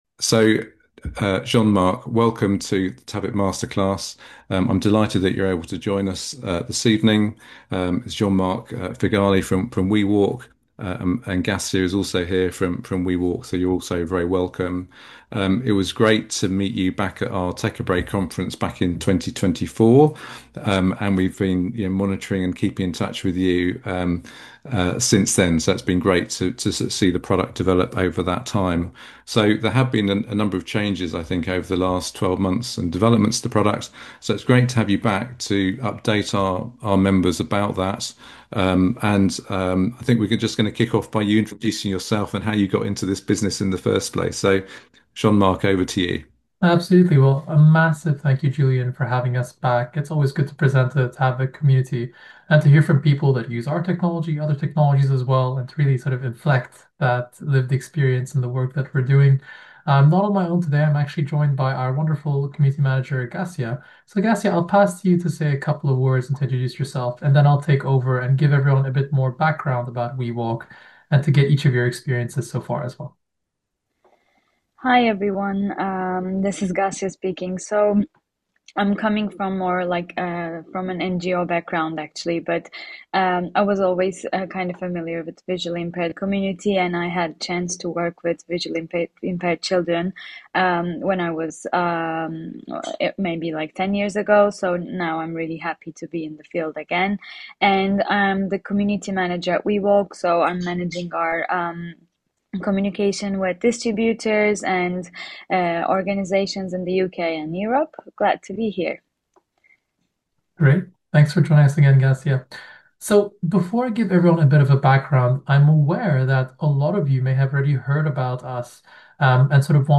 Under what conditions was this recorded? The webinar included an overview of the updated Intelligent Voice Assistant and how it can be used in practice. The session also highlighted the developments made over the past year and present real-life use cases from customers.